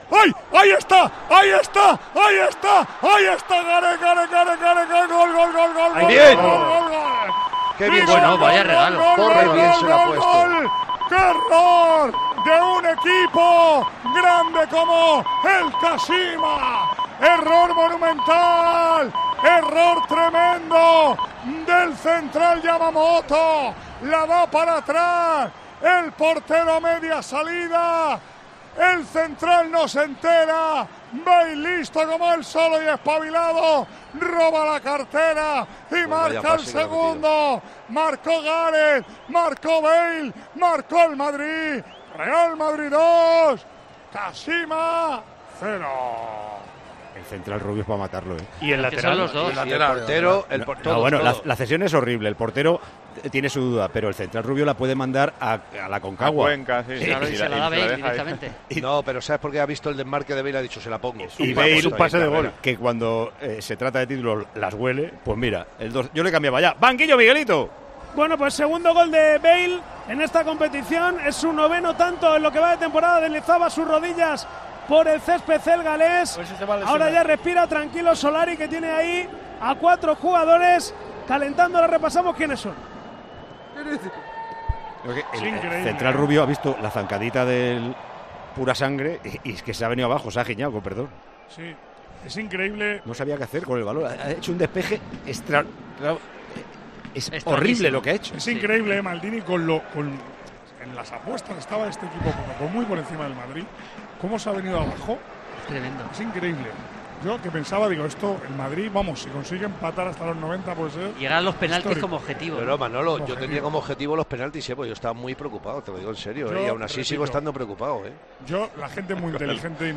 Vecinos de el Campillo (Huelva), han saltado el cordón policial para increpar